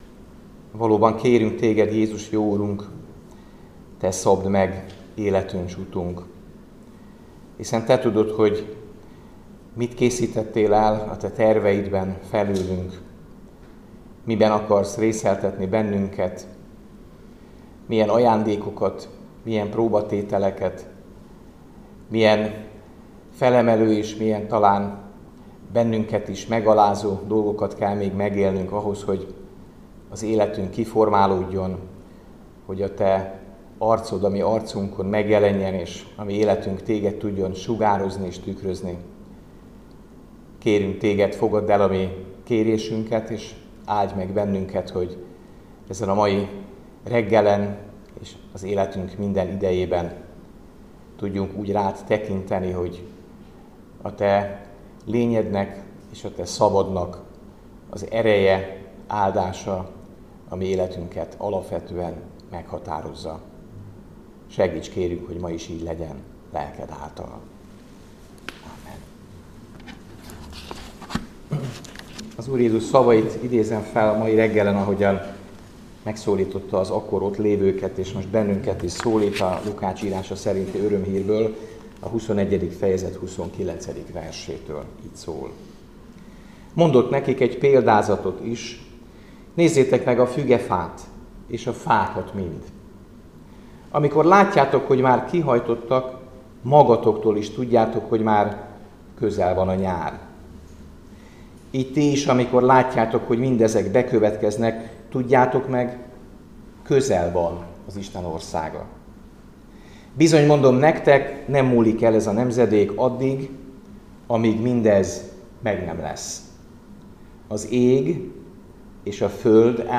Áhítat, 2025. április 8.